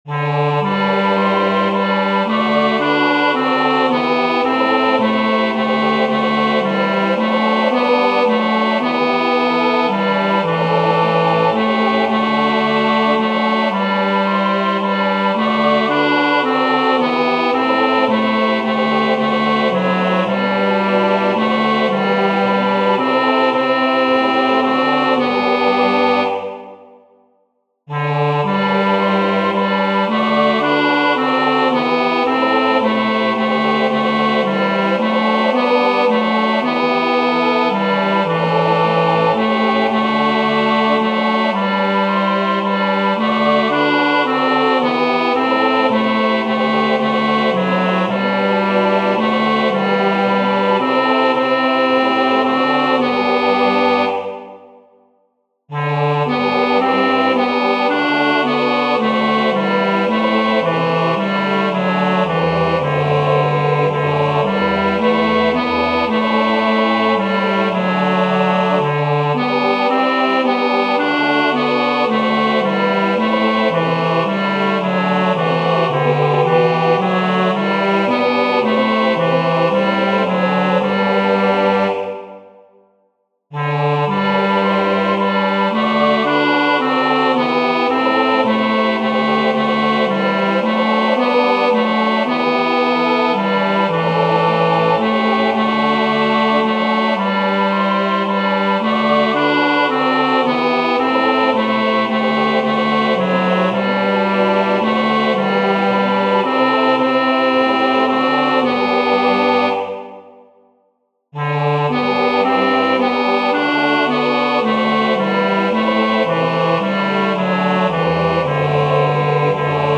Vers 2: p. Vanaf maat 9 langzaam crescendo tot mf op de slotnoot
Tenor uitgelicht